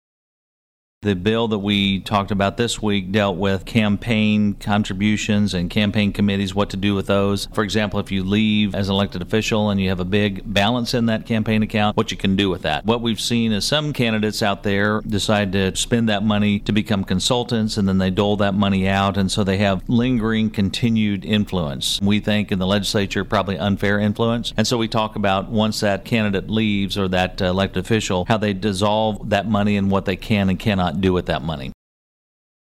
Senator Pearce says Missouri senators spent more time debating ethics reform legislation.